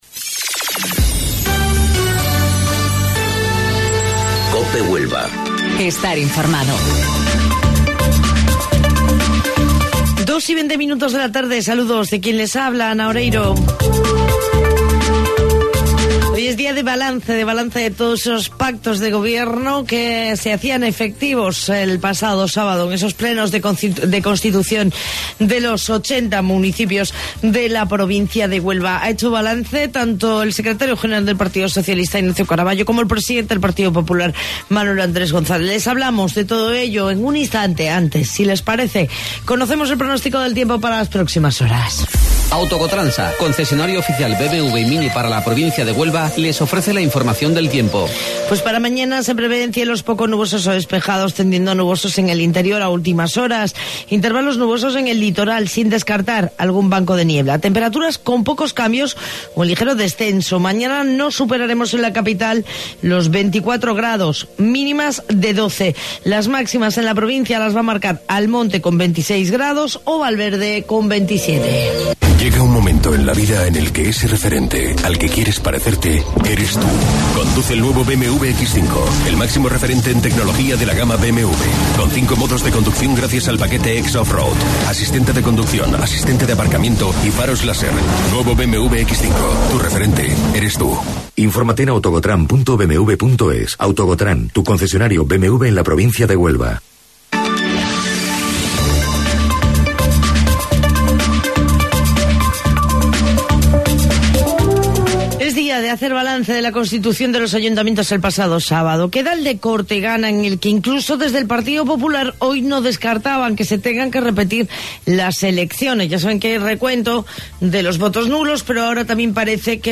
AUDIO: Informativo Local 14:20 del 17 de Junio